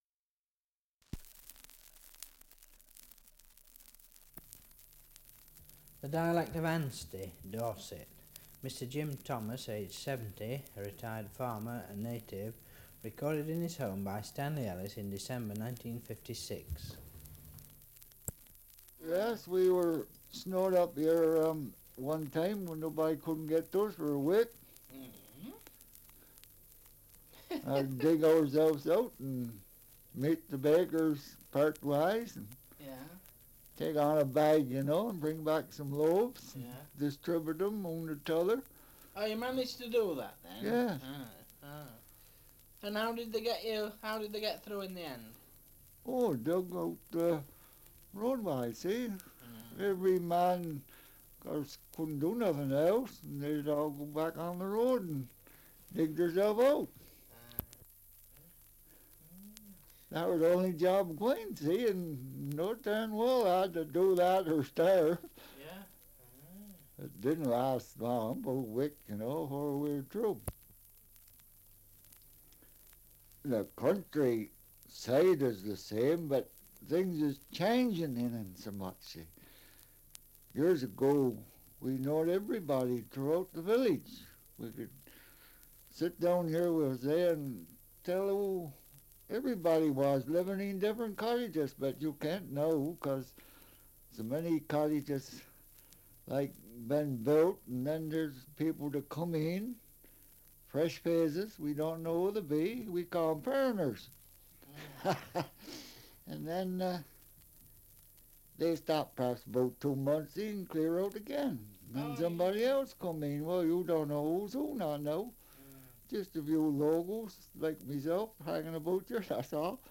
Survey of English Dialects recording in Ansty, Dorset
78 r.p.m., cellulose nitrate on aluminium